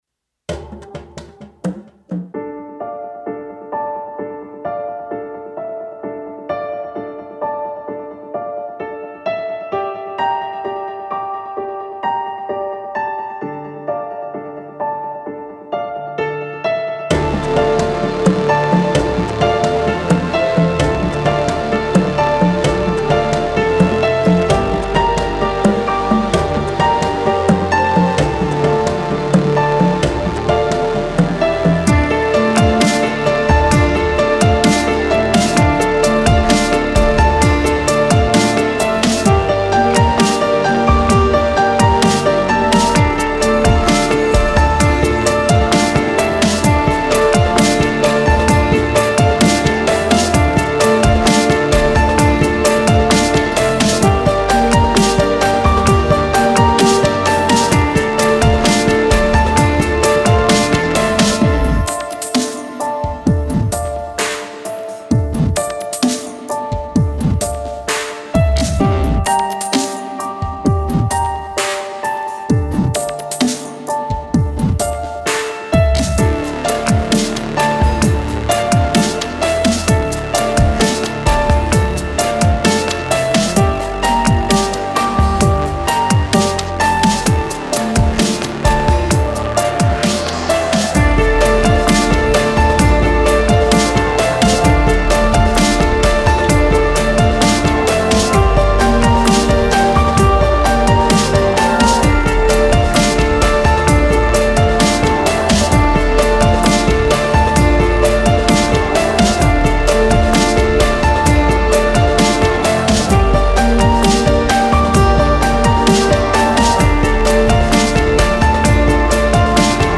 Genre: Soundtrack
electro
ambient
instrumental
sad
piano
synths
rhythmic